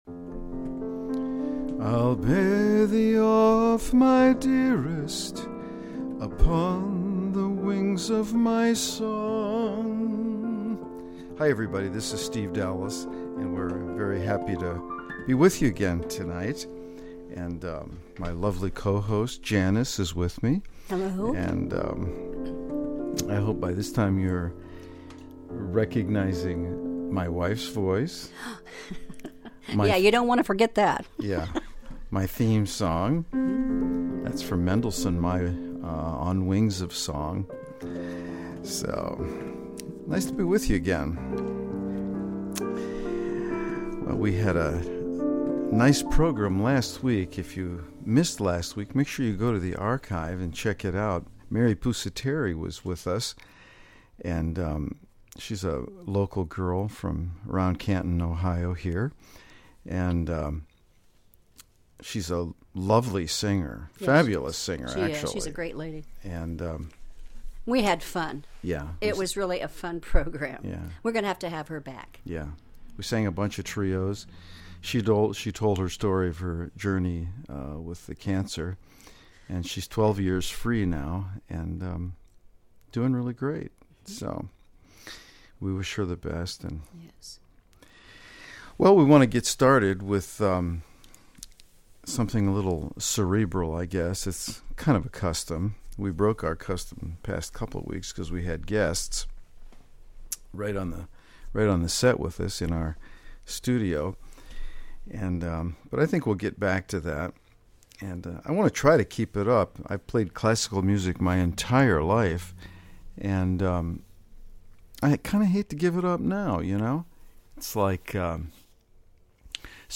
Golden oldies played to perfection!